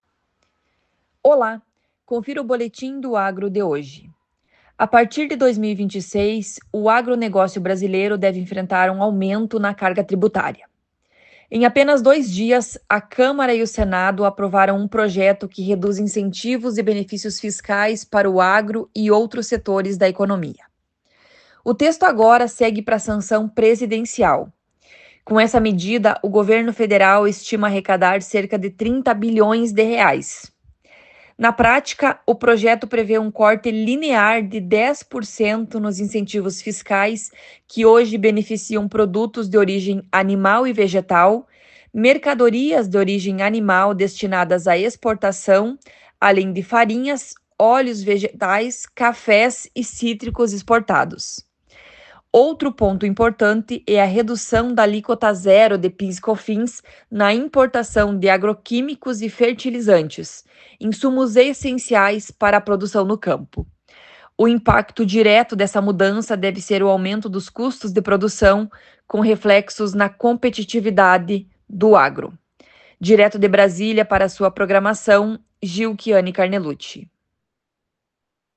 Boletim do Agro de hoje
Jornalista, especialista em agronegócio